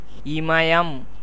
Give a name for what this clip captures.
pronunciation; transl.